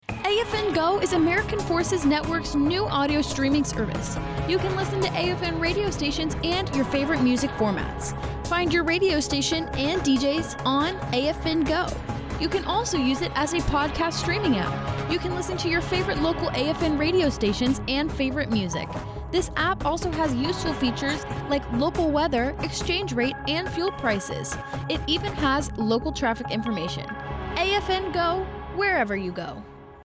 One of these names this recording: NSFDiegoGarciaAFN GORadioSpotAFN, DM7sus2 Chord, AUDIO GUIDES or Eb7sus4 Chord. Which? NSFDiegoGarciaAFN GORadioSpotAFN